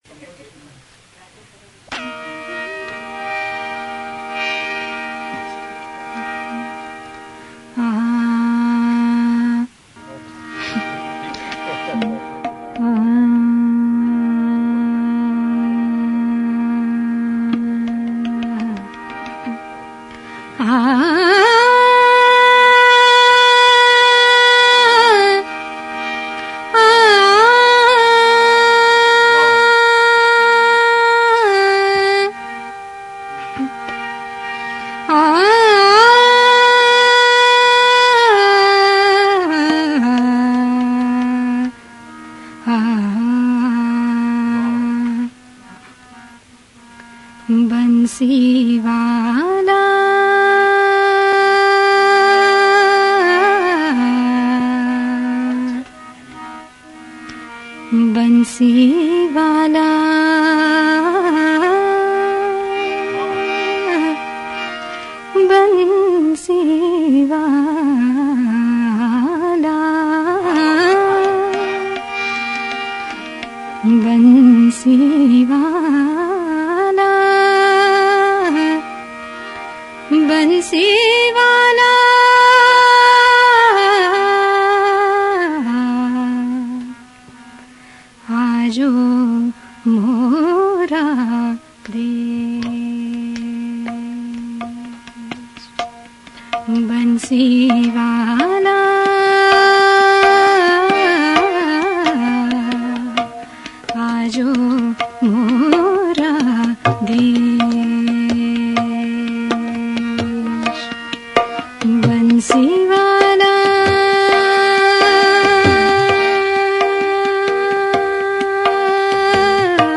live excerpt / Bhajan / 1978